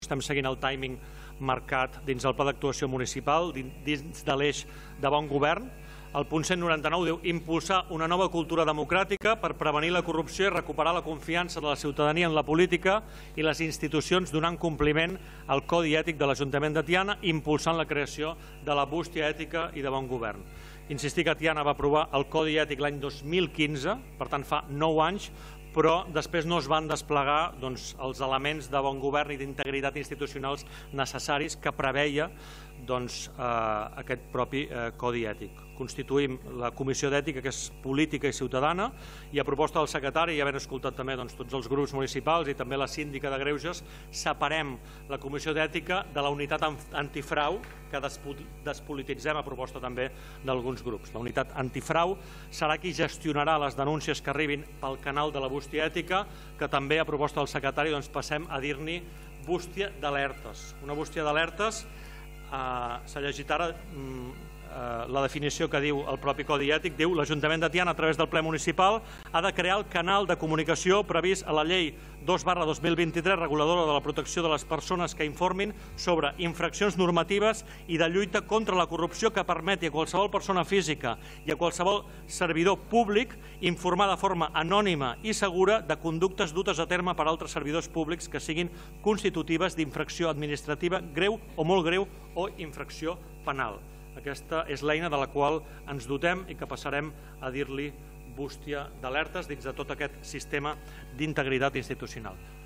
L’alcalde de Tiana, Isaac Salvatierra, va explicar que aquesta modificació forma part del full de ruta marcat al Pla d’Actuació Municipal (PAM):